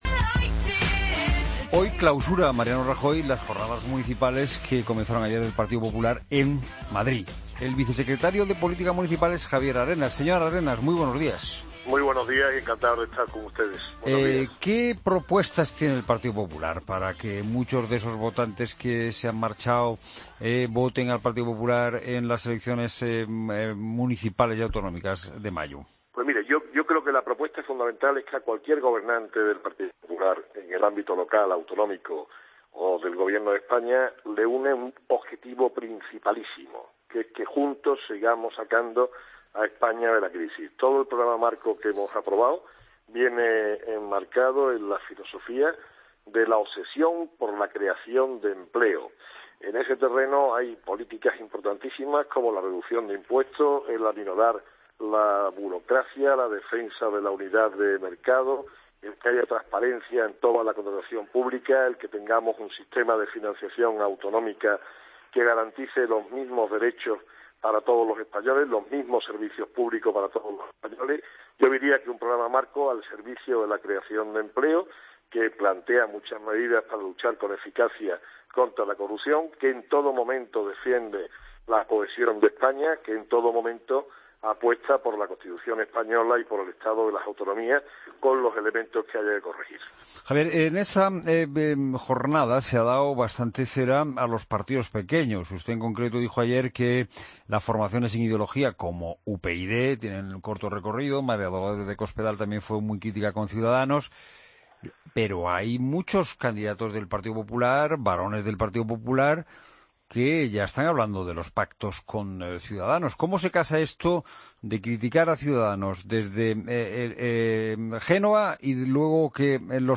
Escucha la entrevista a Javier Arenas en La Mañana Fin de Semana